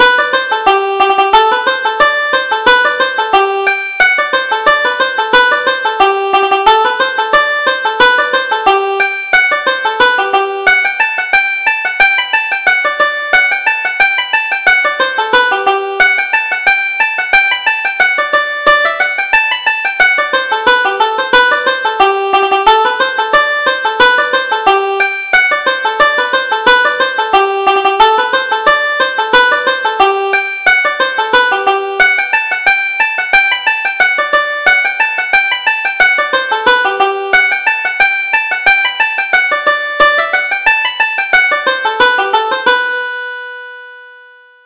Reel: The Ladies' Pantalettes